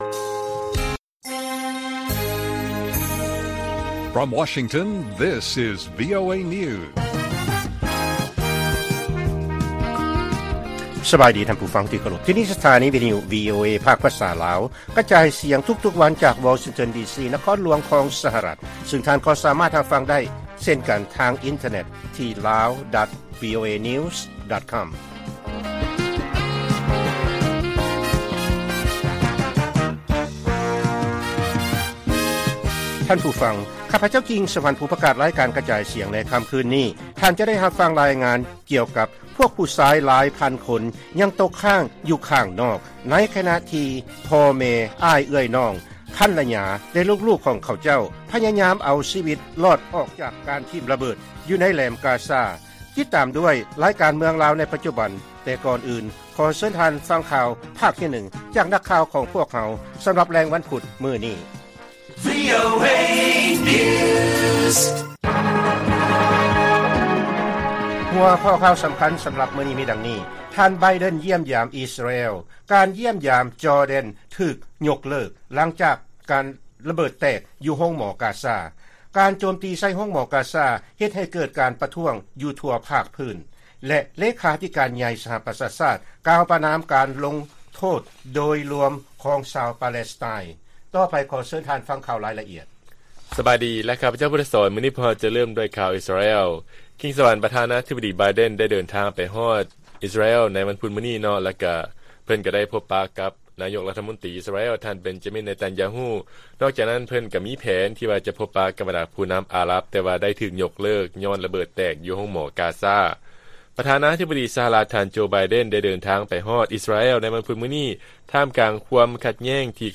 ລາຍການກະຈາຍສຽງຂອງວີໂອເອ ລາວ: ທ່ານ ໄບເດັນ ຢ້ຽມຢາມ ອິສຣາແອລ, ການຢ້ຽມຢາມ ຈໍແດັນ ຖືກຍົກເລີກ ຫຼັງຈາກລະເບີດແຕກຢູ່ໂຮງໝໍ ກາຊາ